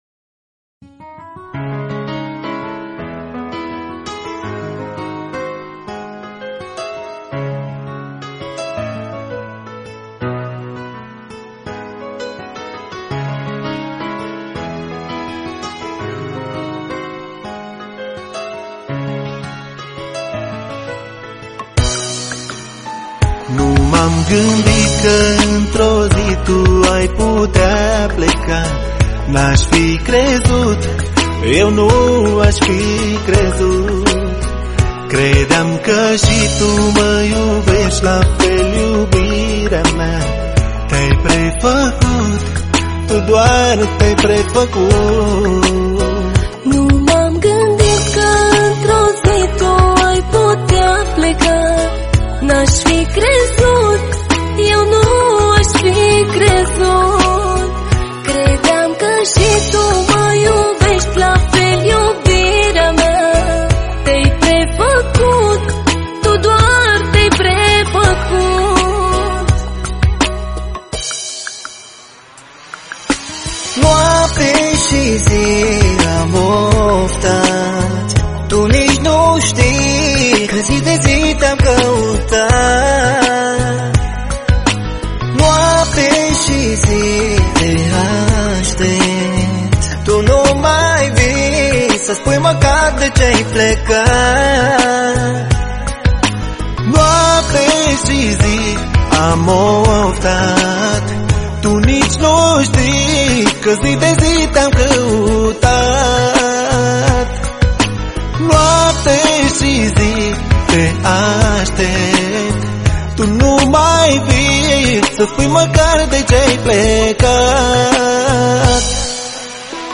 Manele Vechi